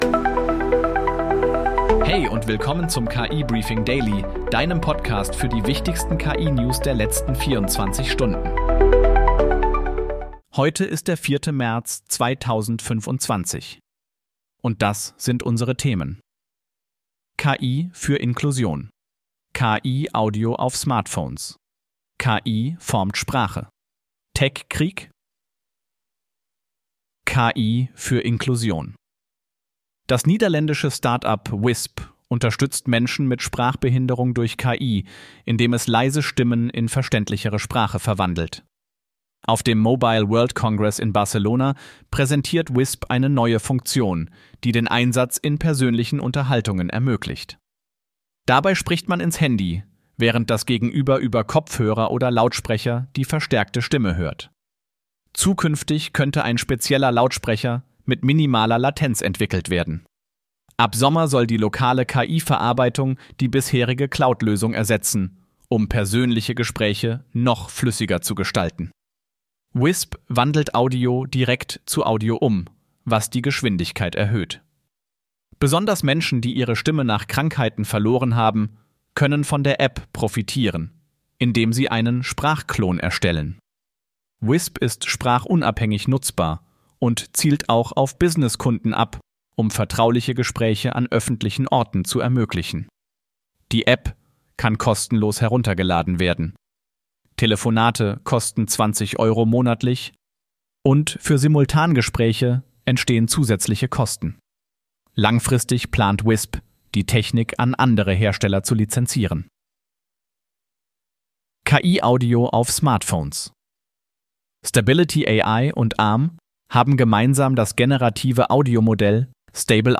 Möchtest du selbst einen solchen KI-generierten und 100% automatisierten Podcast zu deinem Thema haben?